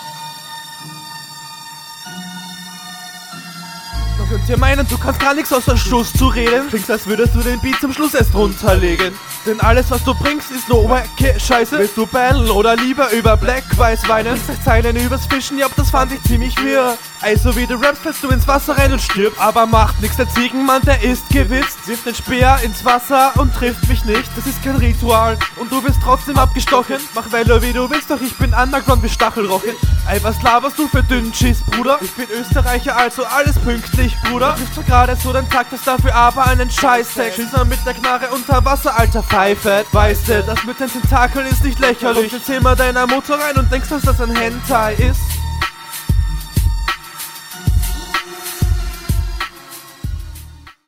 heyy, da doubelt ja jemand haha. konter mit dem beat drunterlegen ist gut. die wasser …